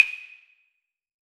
6CLAVE.wav